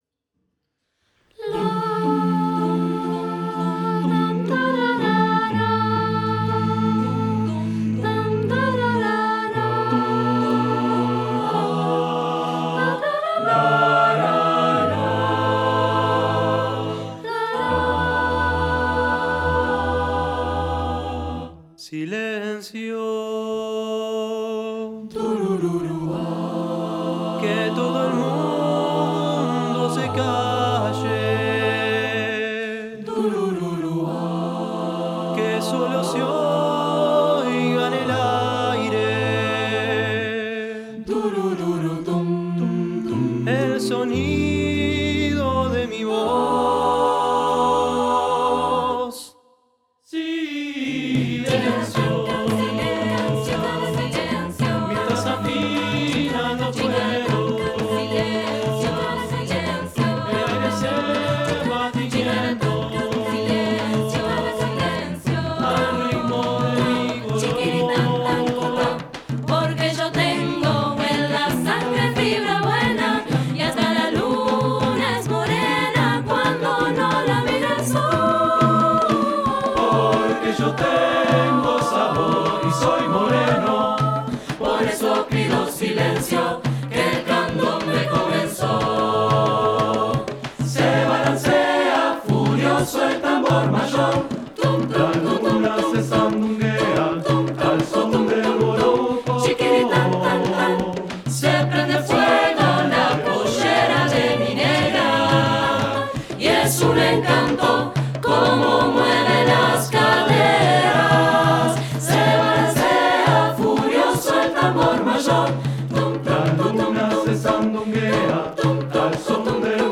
• Voicing: sasa, satb
• Solo: T